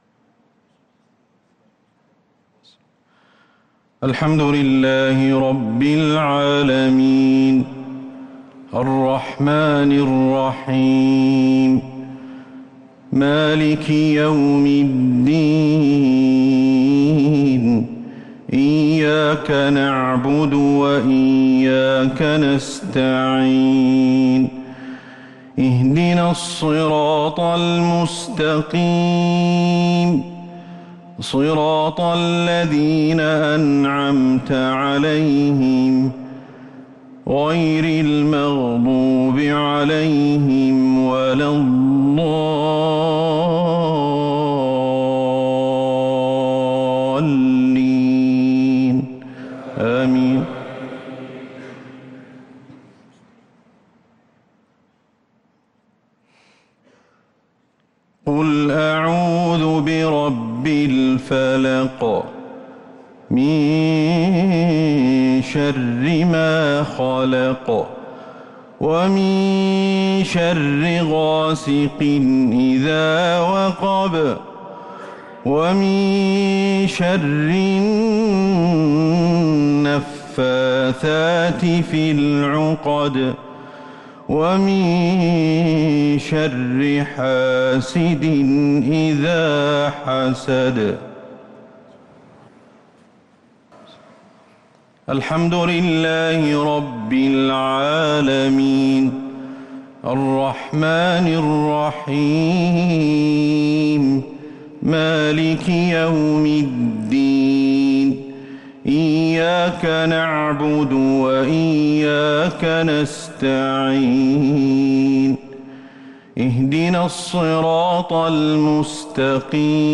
صلاة العشاء للقارئ أحمد الحذيفي 27 رمضان 1443 هـ
تِلَاوَات الْحَرَمَيْن .